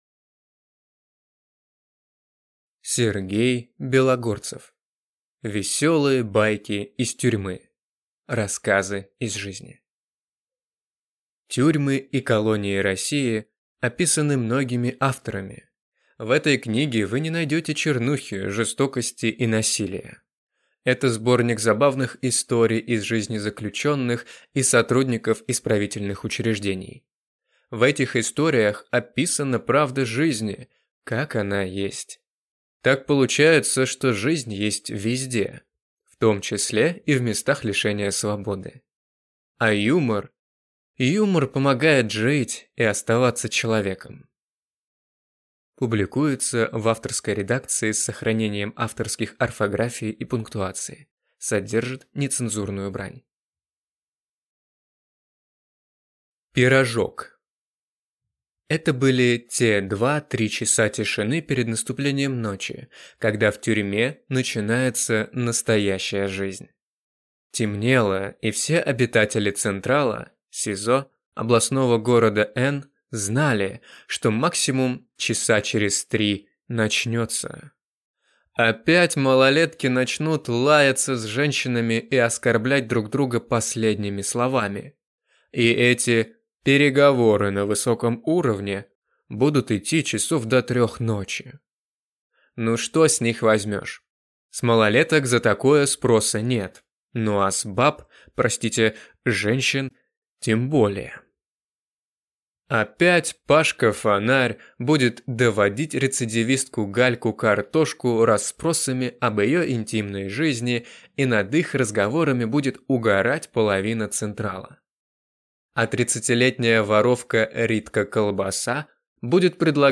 Аудиокнига Веселые байки из тюрьмы | Библиотека аудиокниг